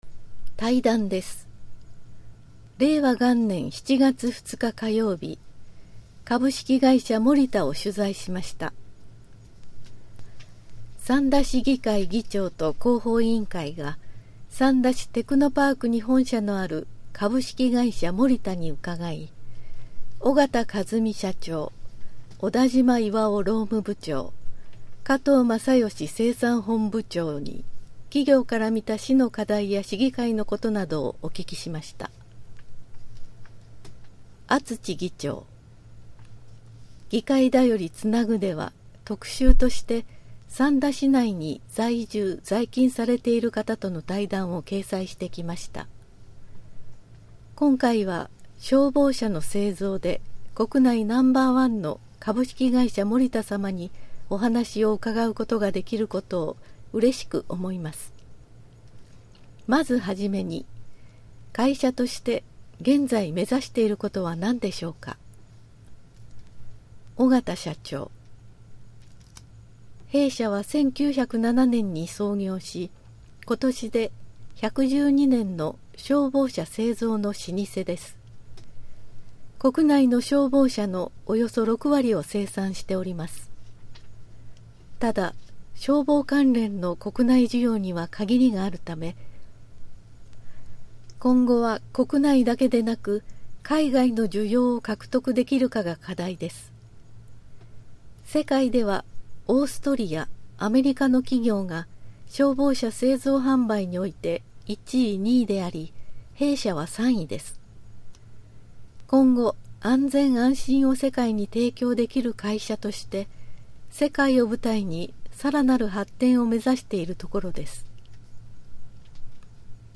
対談「株式会社モリタ」 6分57秒 (音声ファイル: 3.2MB)